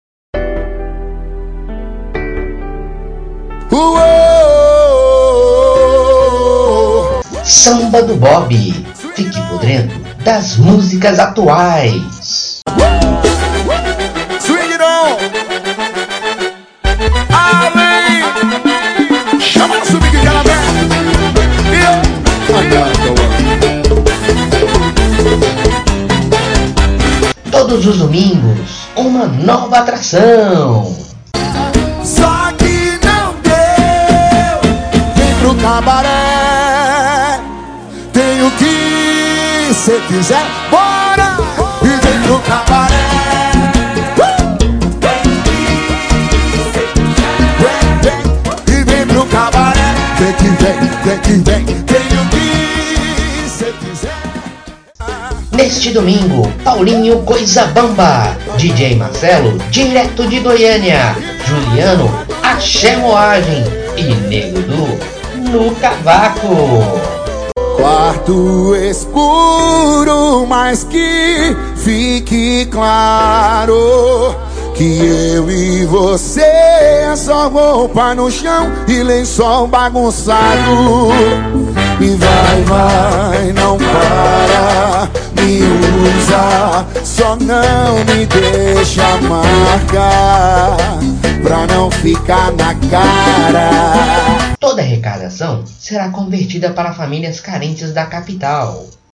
ÁUDIOS SPOT PUBLICITÁRIOS